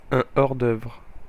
An hors d'oeuvre (/ɔːr ˈdɜːrv(rə)/ or DURV(-rə); French: hors-d'œuvre [ɔʁ dœvʁ(ə)]
Fr-hors_d_oeuvre-fr-Paris.ogg.mp3